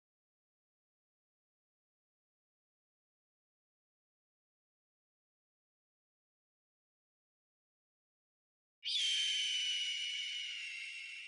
老鹰叫声
描述：老鹰叫声。
标签： 老鹰 叫声
声道单声道